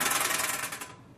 Locker Door Metal Rattle